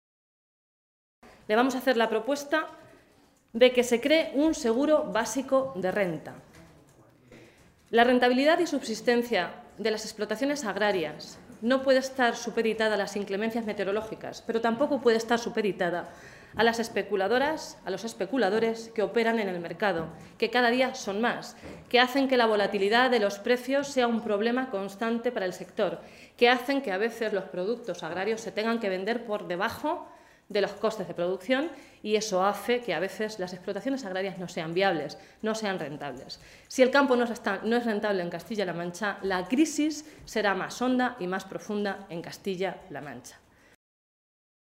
Blanca Fernández, portavoz de Agricultura del Grupo Parlamentario Socialista
Cortes de audio de la rueda de prensa